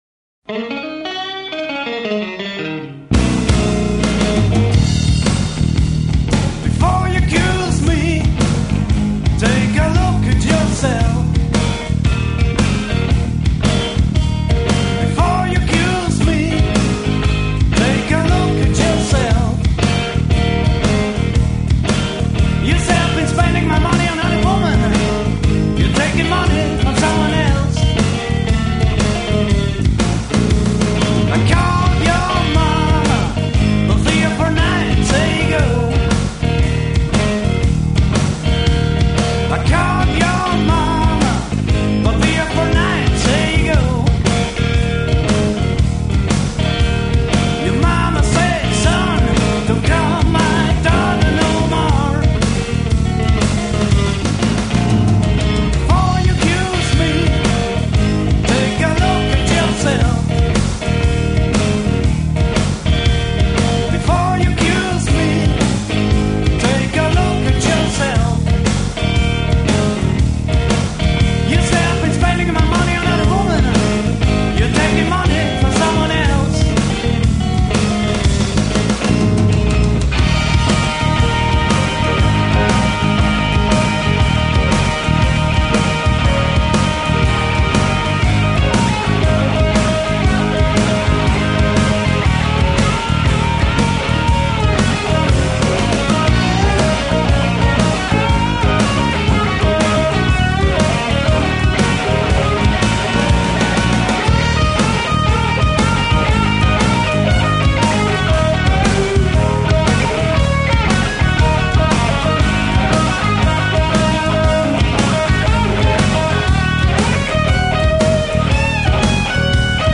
Erste Studioproduktion 1995
Vocals & Bass